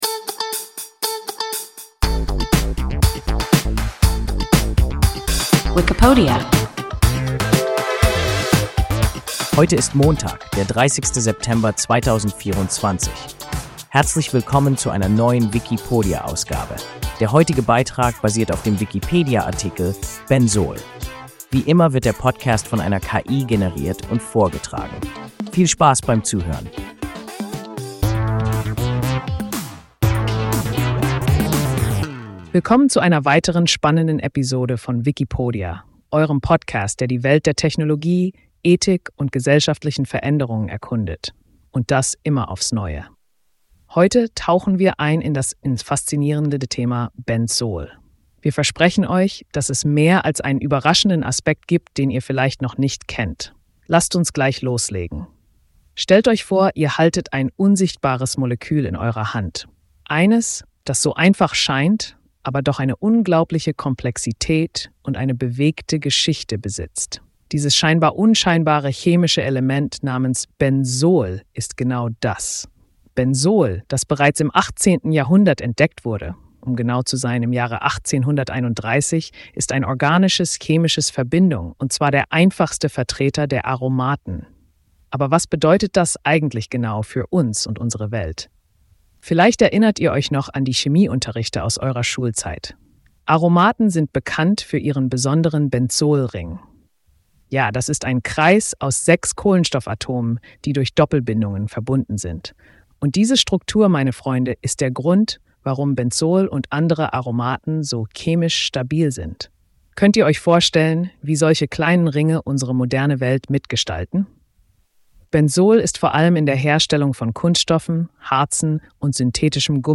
Benzol – WIKIPODIA – ein KI Podcast